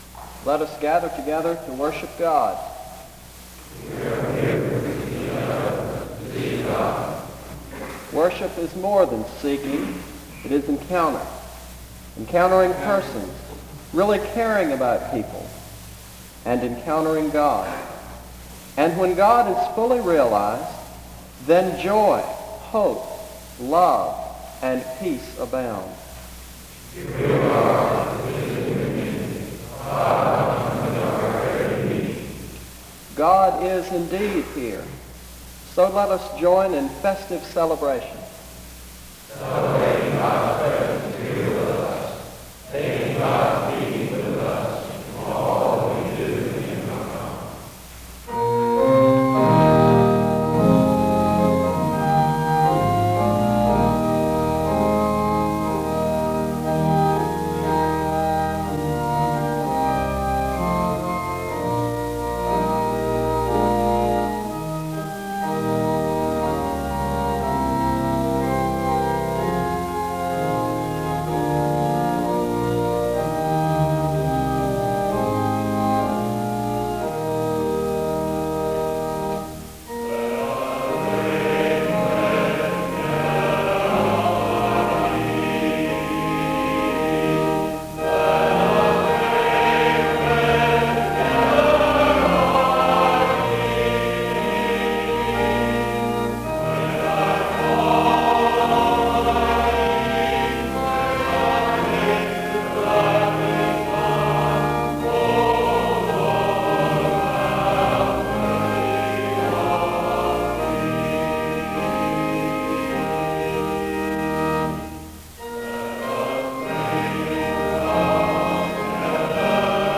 The service starts with a responsive reading from 0:00-0:48. Music plays from 0:48-3:22. A responsive reading takes place from 3:33-5:55. Music plays from 6:01-10:00.